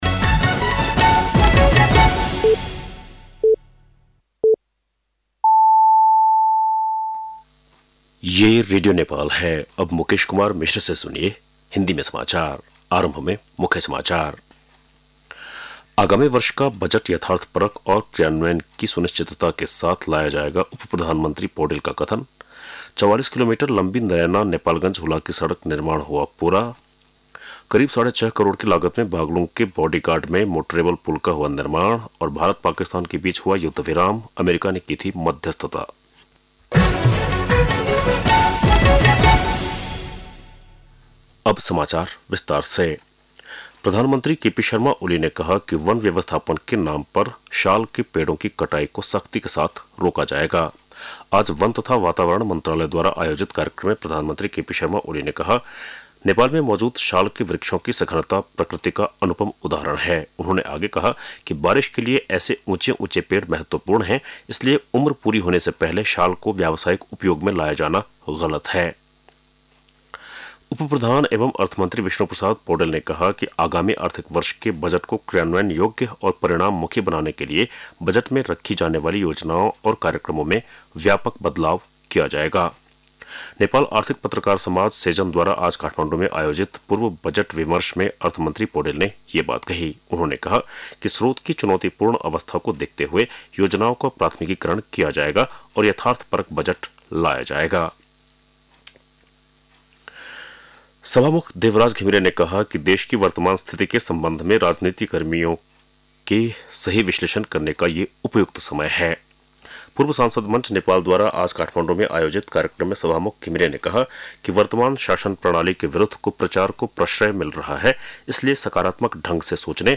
बेलुकी १० बजेको हिन्दी समाचार : २७ वैशाख , २०८२